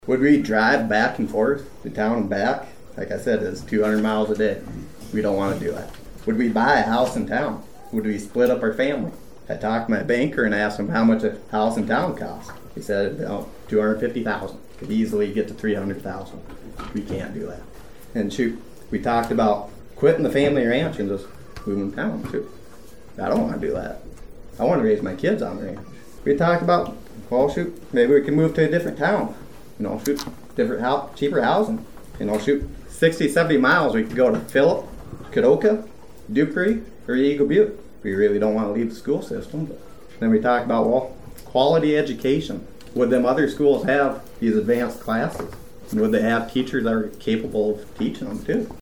During the public comment portion, about a dozen residents– all of them opposed– addressed the possibility of closing the school.